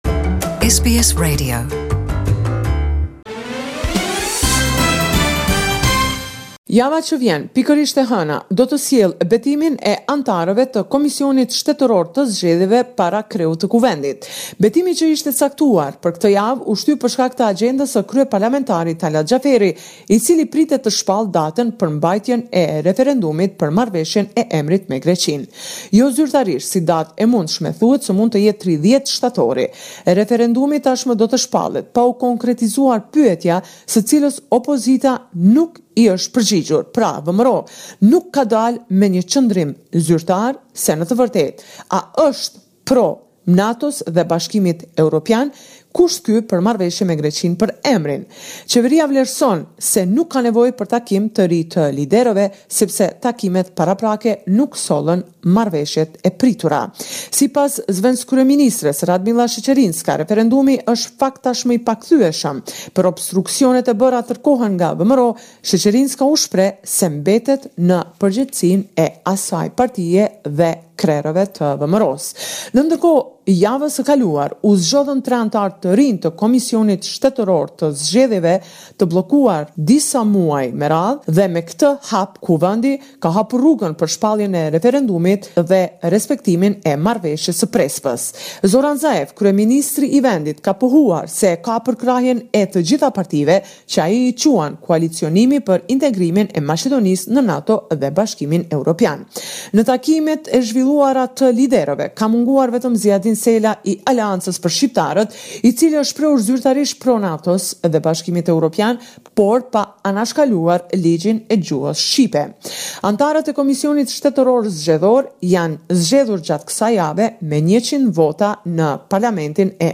The weekly report with the latest developments in Macedonia.